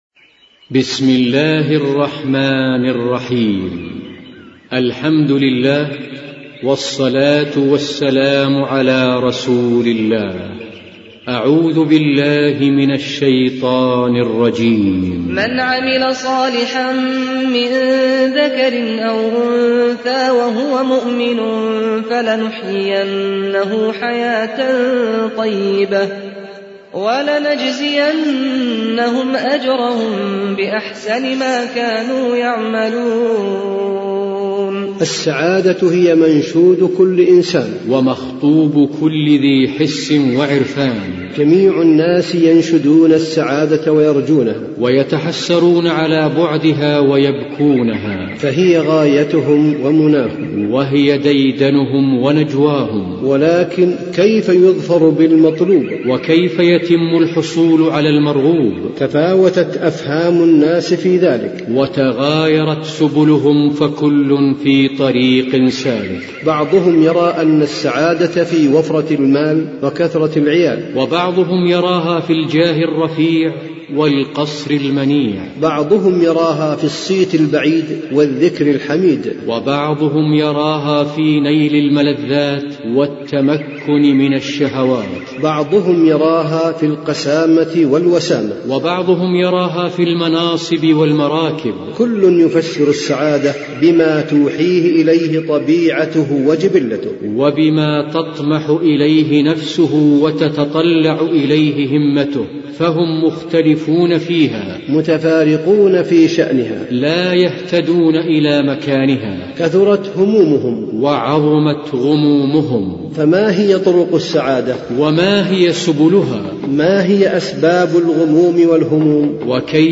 قراءة كتاب الوسائل المفيدة للحياة السعيدة